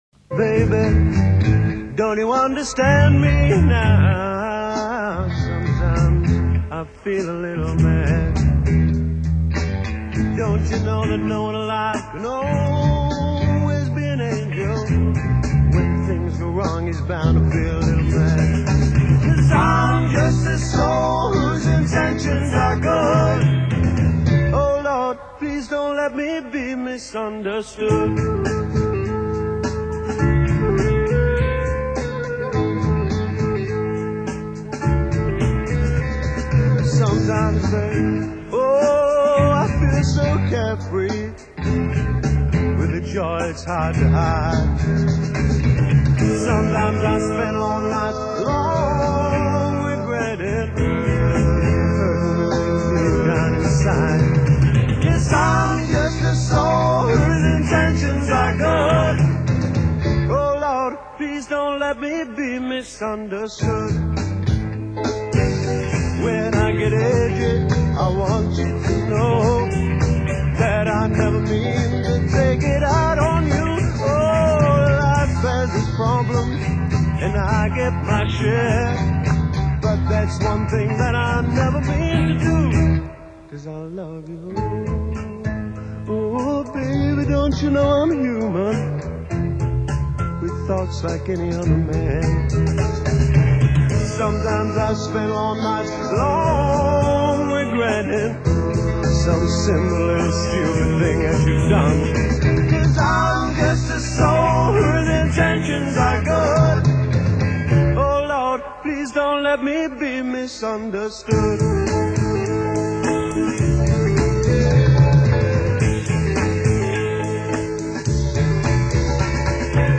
in session at The BBC in 1967
Mellotron
Classical Rock